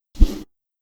Melee Weapon Air Swing 6.wav